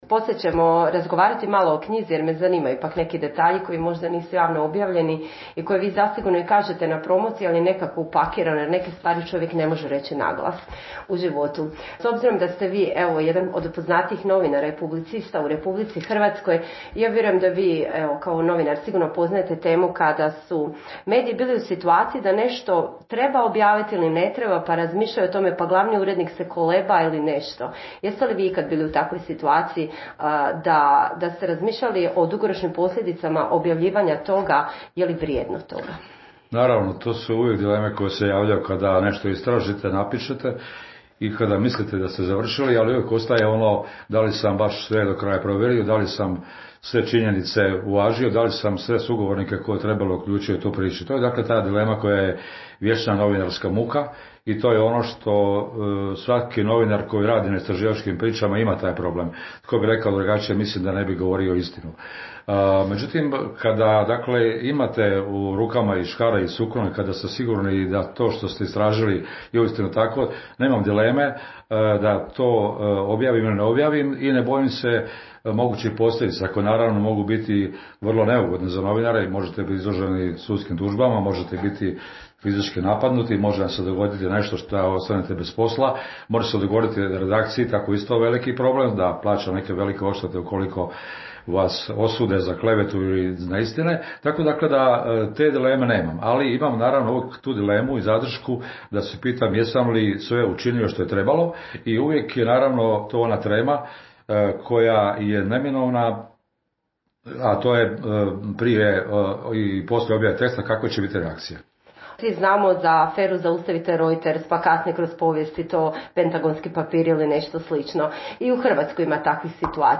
Donosimo intervju s jednim od najistaknutijih hrvatskih novinara – Dragom Hedlom.